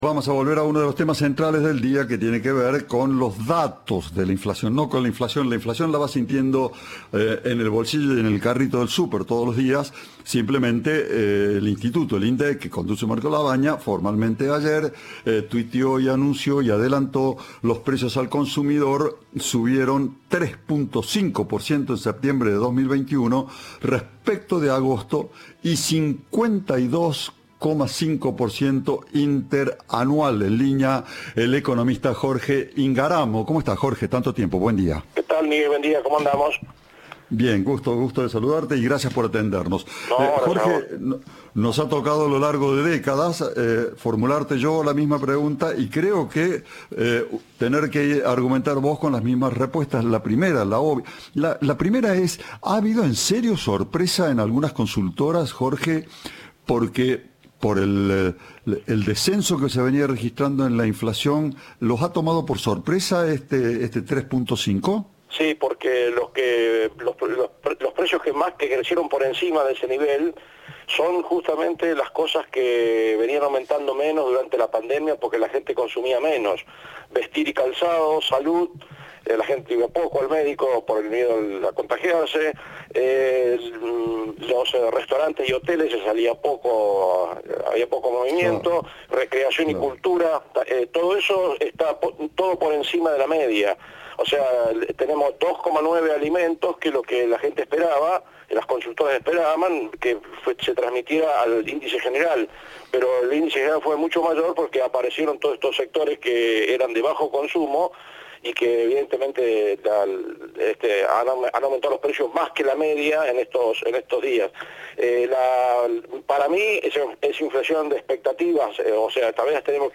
El economista admitió que no esperaban un número tan elevado, pero indicó a Cadena 3 que otras variables, como el tipo de cambio, aún están "muy atrasadas".